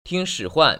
[bù tīng shĭ·huan] 뿌팅스후안